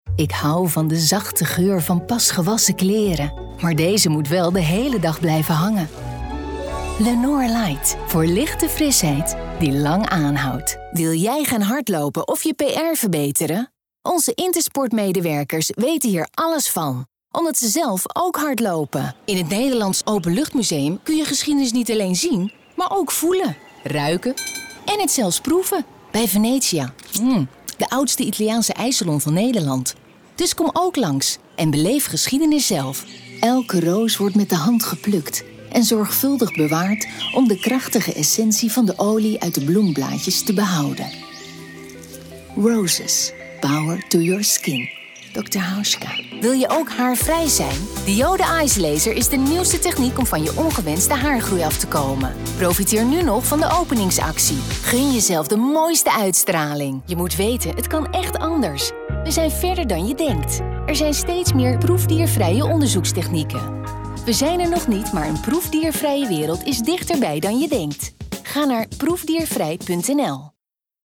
Voz
Comercial, Seguro, Amable, Cálida, Empresarial
Comercial